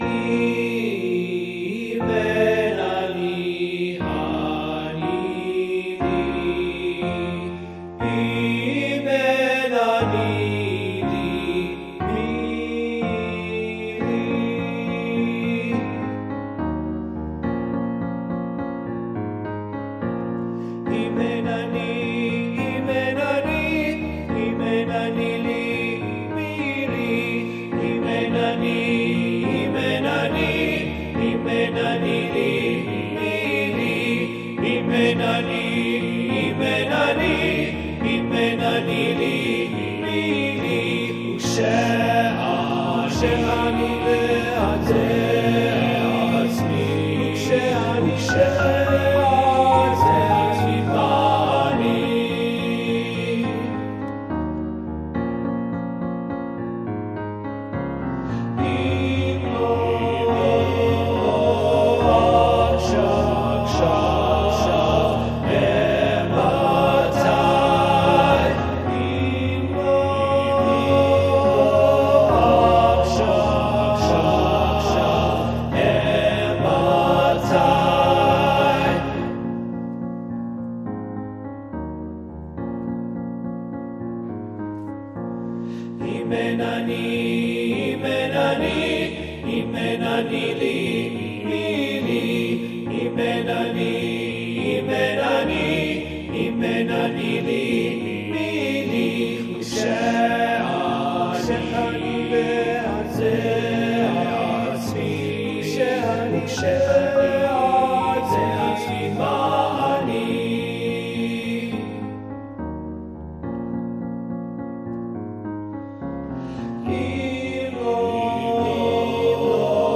Voicing: "TBB"